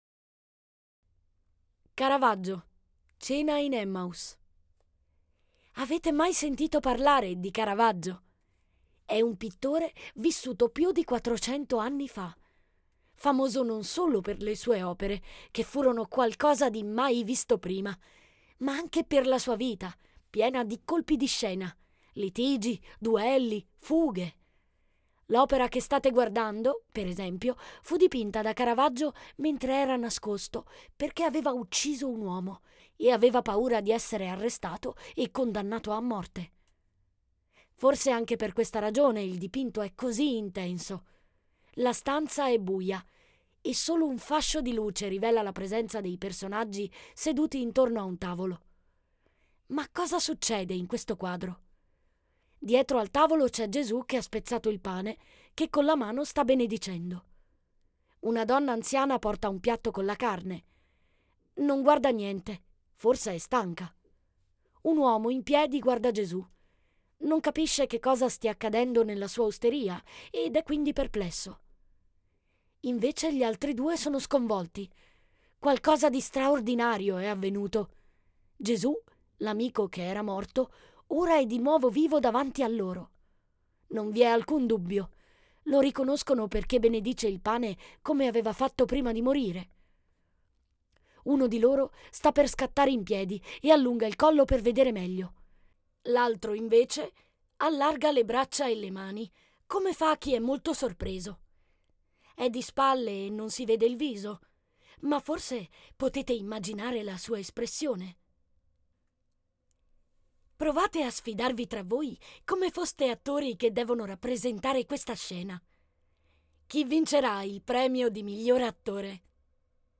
Audioguida "Brera in famiglia"